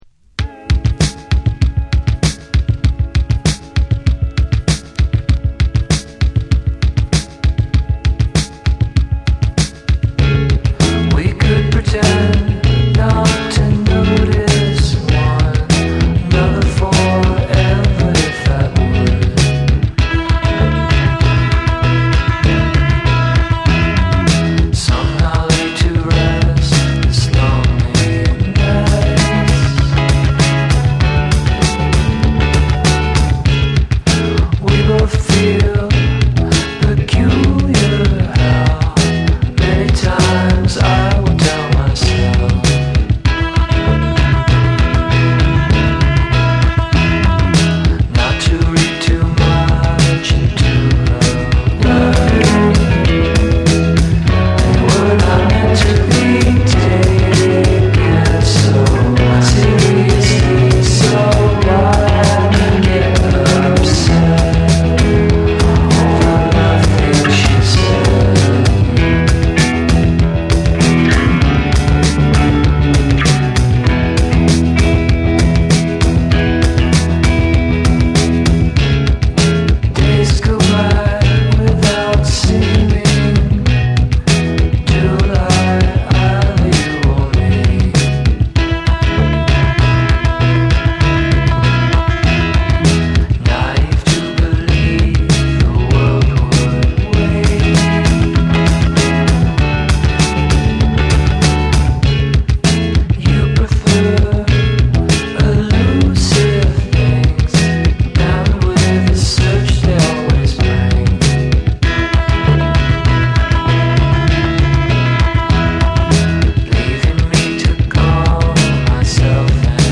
USフォーク・ロック・バンド
オーガニックでメロウなフォークサウンドに加え、モダンな解釈も楽しめる一枚。
NEW WAVE & ROCK / BREAKBEATS